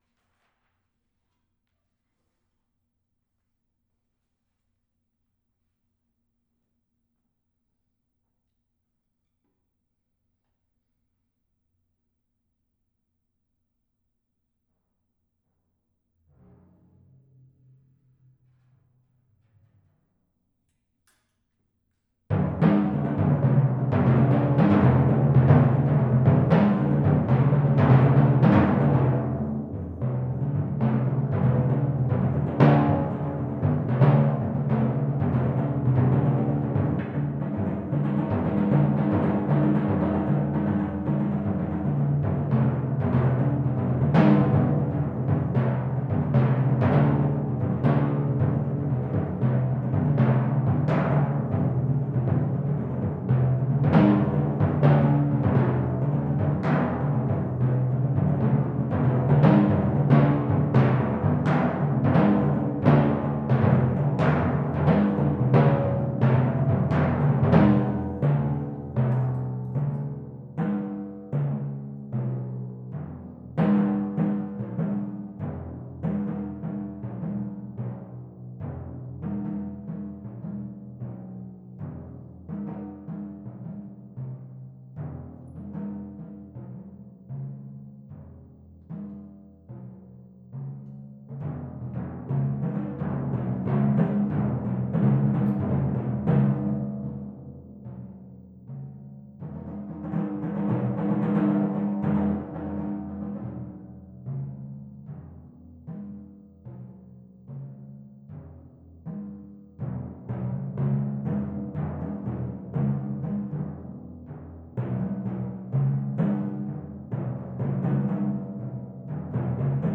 Mvt. 3 of Three Movements for Timpani (world premiere) by Tony Edwards; performed live at my Master's recital, April 3, 2010, at The University of Texas at Austin
07+Three+Movements+for+Timpani_+III..m4a